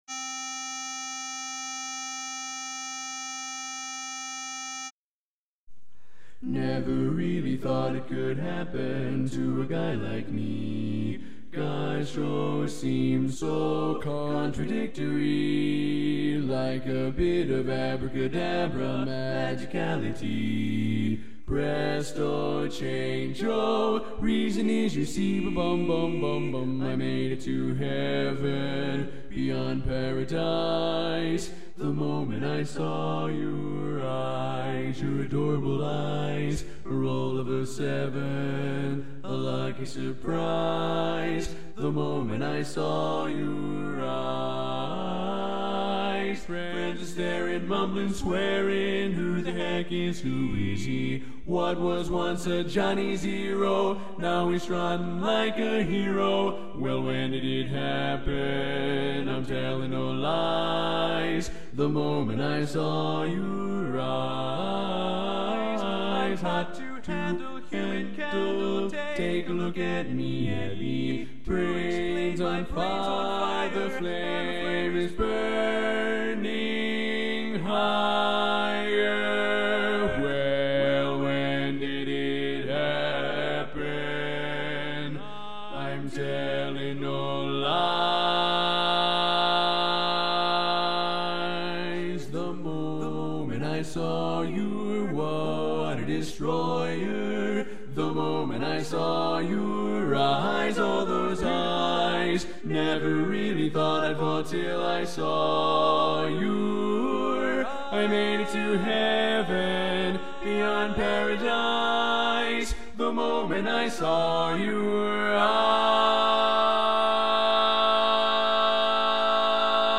Kanawha Kordsmen (chorus)
Up-tempo
C Major